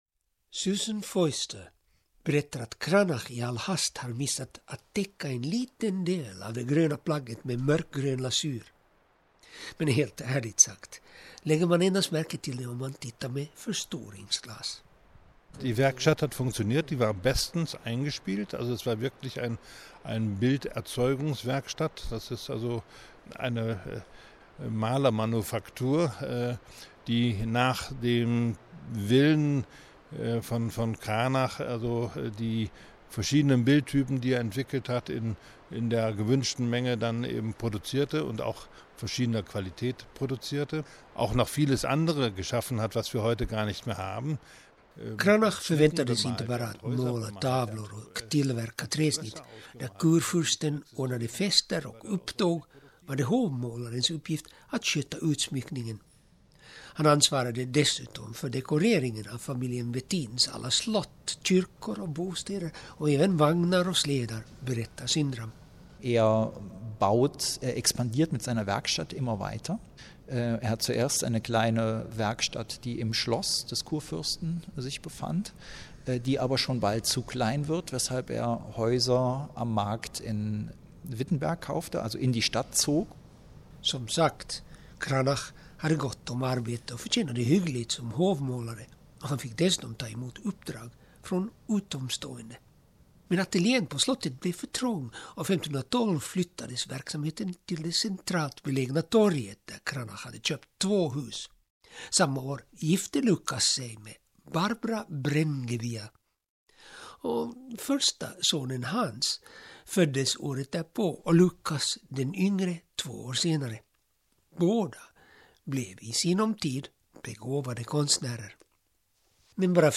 Jag har intervjuat två brittiska och två tyska Cranach experter för detta program: